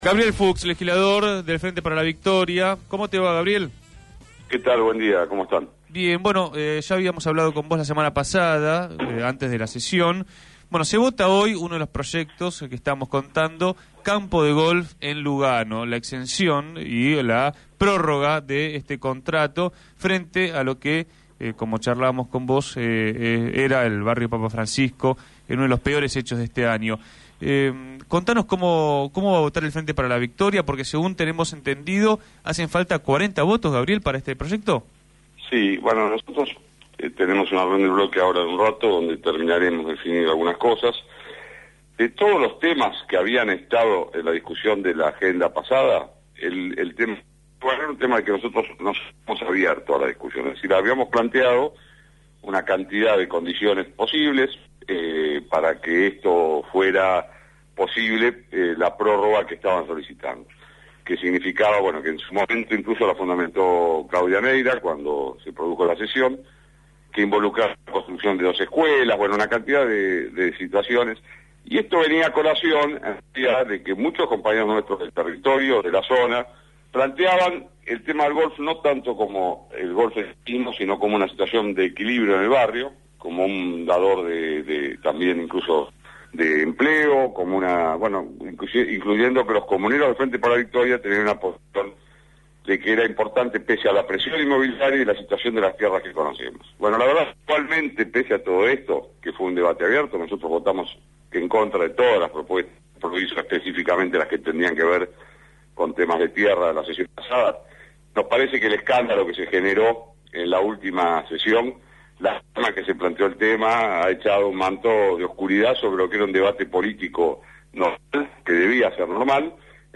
Gabriel Fuks, legislador por el Frente Para la Victoria, planteó su posición minutos antes de la reunión de bloque, en comunicación con Punto de Partida.